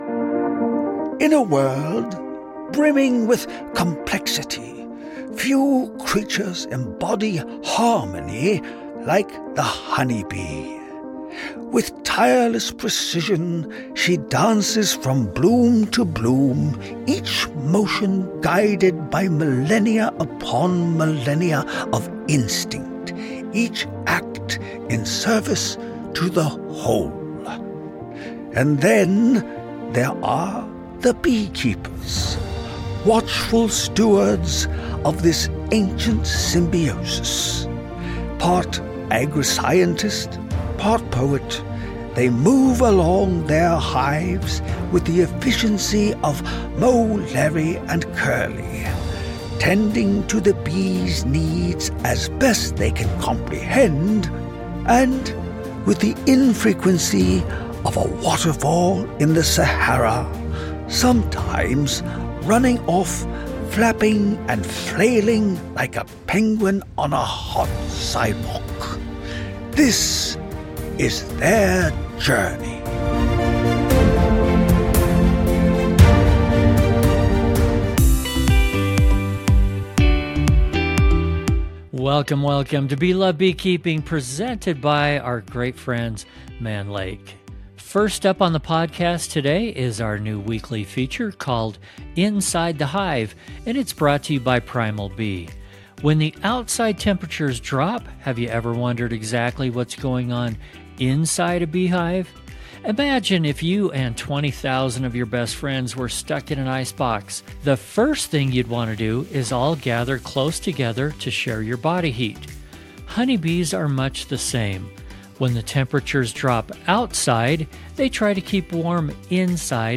Live From NAHBE Interview